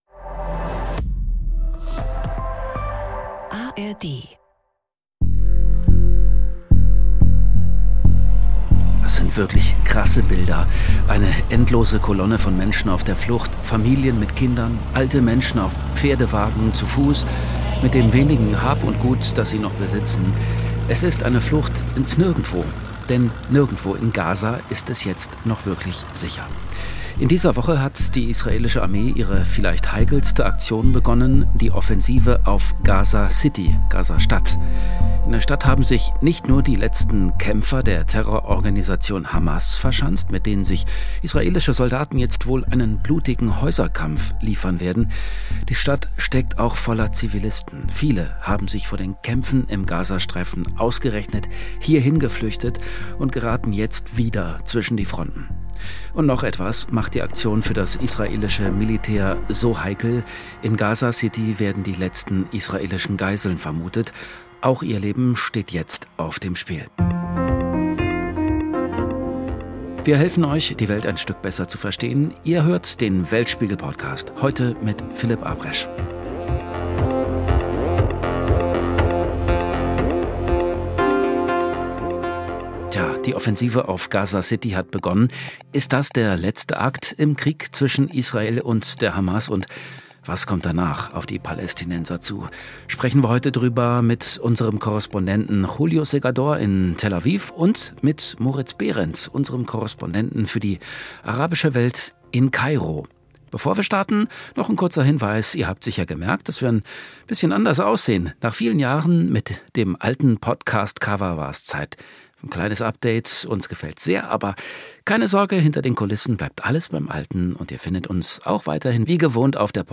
Weltspiegel Podcast Gaza-Stadt: Israels Offensive trotz heftiger Kritik Play episode September 19 29 mins Bookmarks View Transcript Episode Description In dieser Woche hat die israelische Armee ihre vielleicht umstrittenste Aktion begonnen: die Offensive auf Gaza City. Was bedeutet diese neue Phase im Krieg zwischen Israel und der Hamas – und was kommt danach? Darüber spricht Host